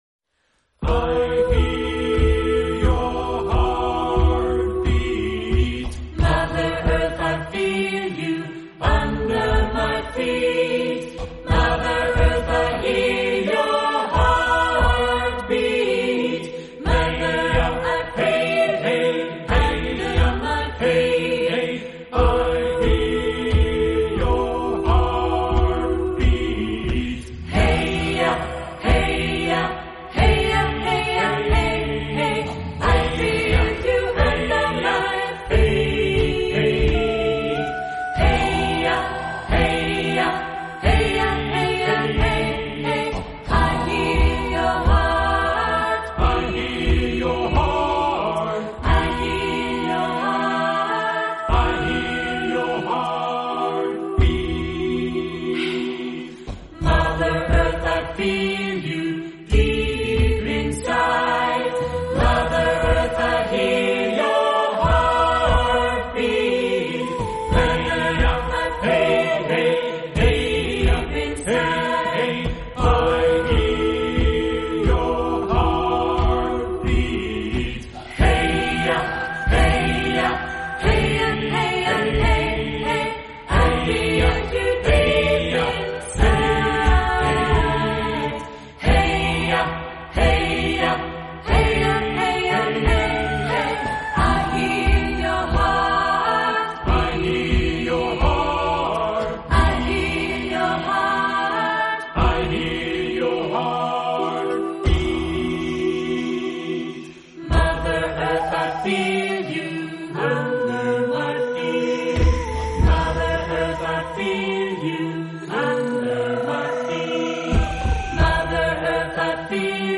SATB + Drums + Speaker Vocal Ensemble WHAT4